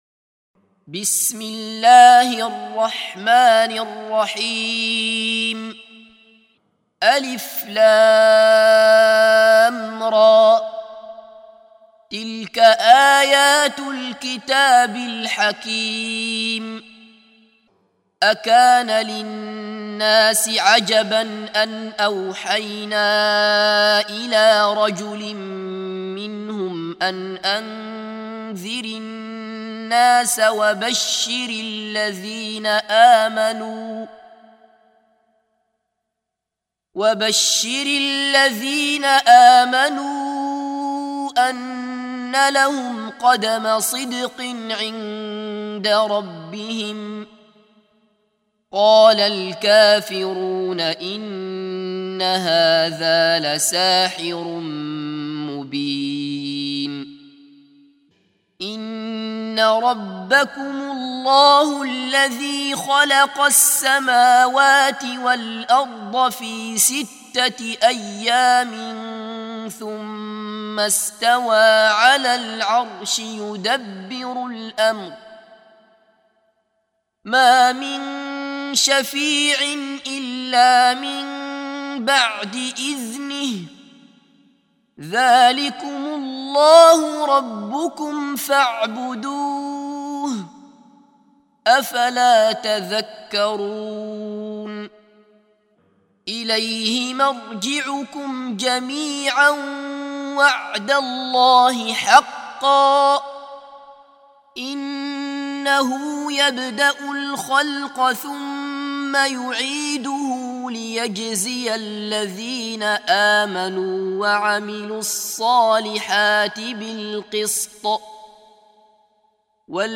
سُورَةُ يُونُسَ بصوت الشيخ عبدالله بصفر